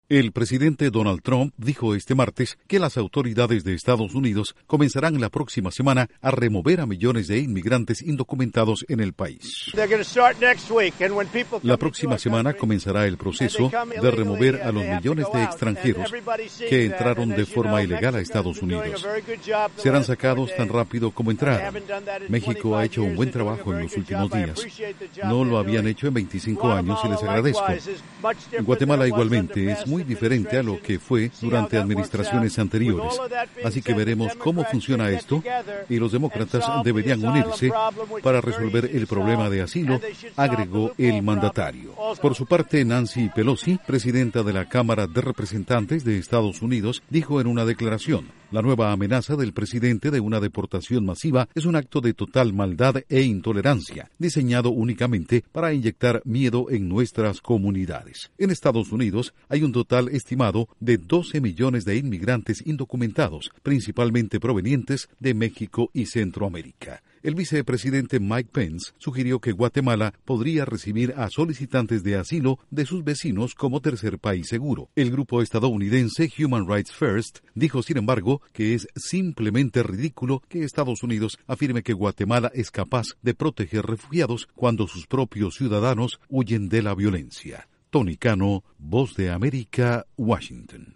Duración: 1:30 Audios de Donald Trump/Presidente EE.UU. Reacción de Nancy Pelosi/Presidenta Cámara de Representantes